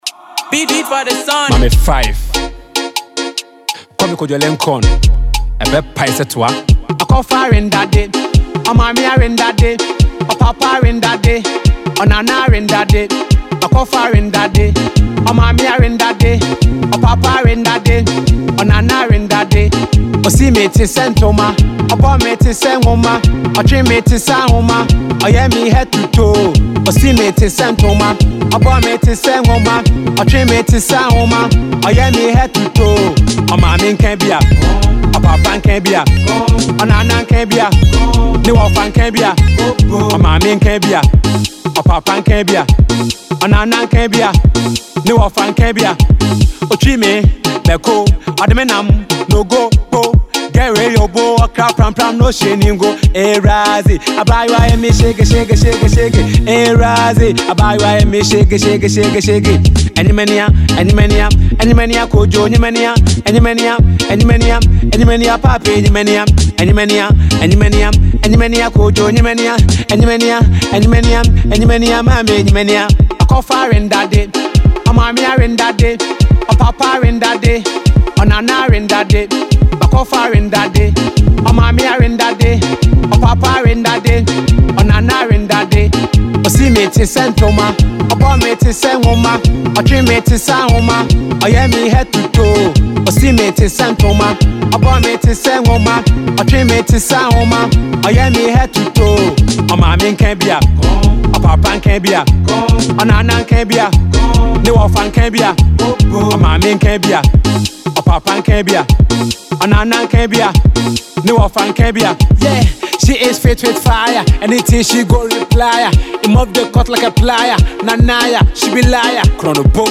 a new hot danceable banger